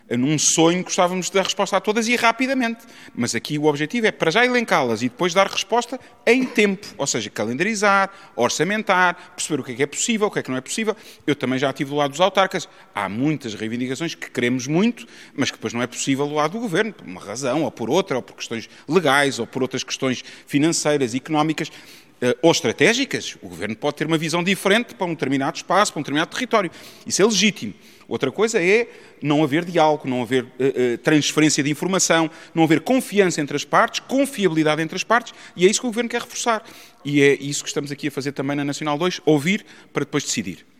Sobre a viagem de quatro dias ao longo dos 738 quilómetros de extensão da EN2, reconheceu a importância de ir ao terreno conhecer os problemas que mais preocupam os autarcas, mas pode não ser fácil dar resposta a tudo: